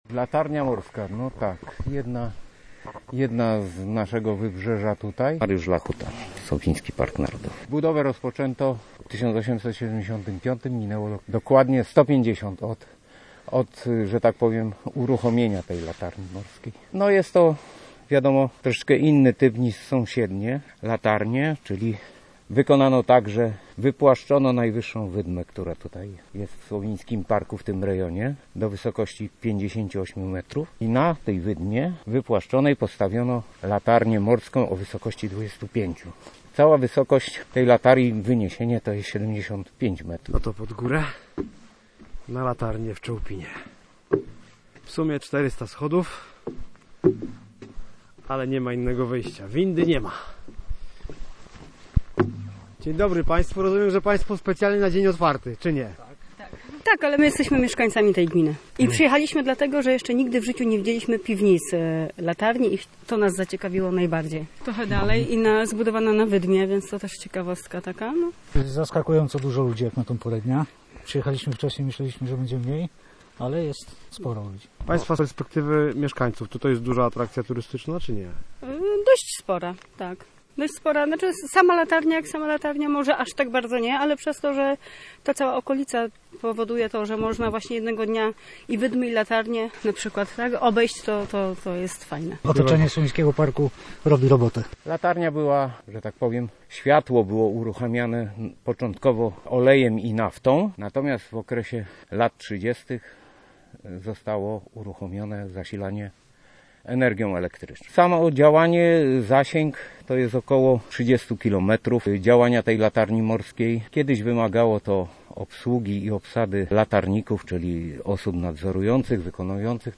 150 lat latarni morskiej w Czołpinie. Byliśmy na dniu otwartym z okazji rocznicy [POSŁUCHAJ]